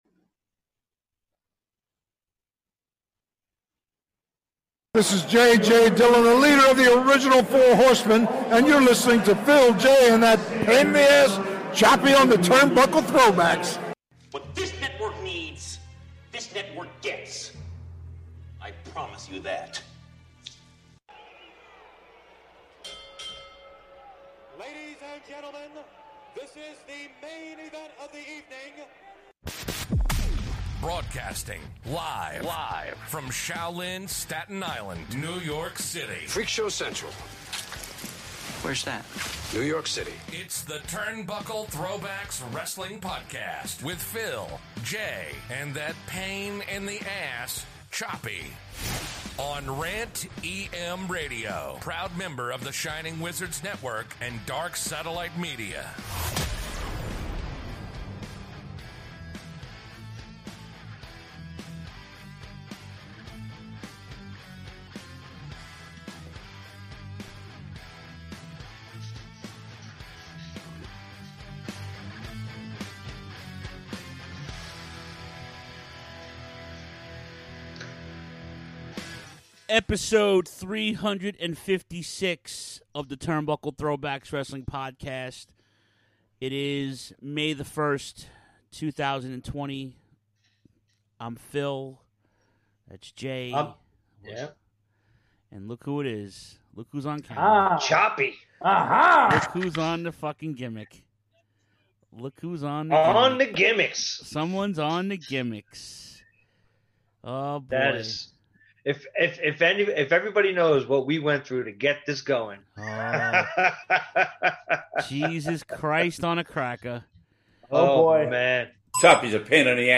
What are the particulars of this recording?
Still Quarantined, the boys still doing the show remotely covering all things current including the rumor of the WWE possibly selling the ranch to EPSN and that famous mouse? This week Throwback is the introduction of AJPW legends Toshiaki Kawada vs Mitsuharu Misawa epic rivalry.